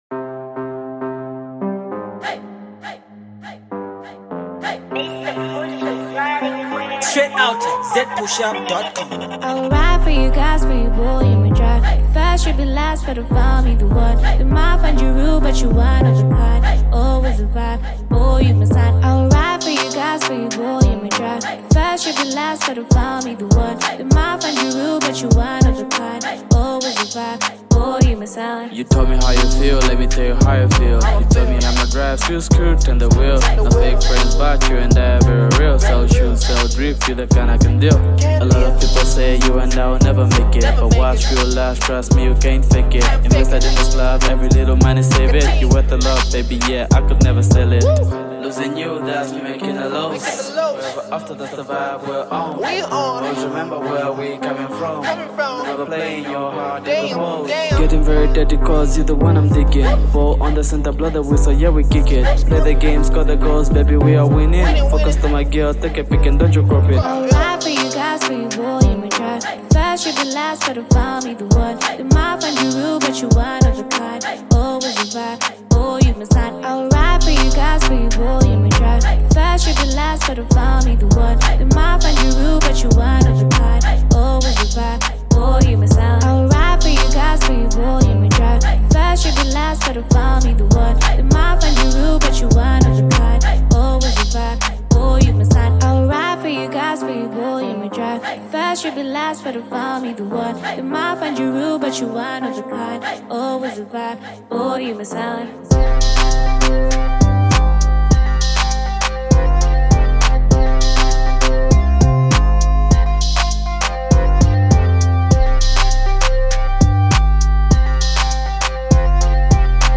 smoothy vibe-able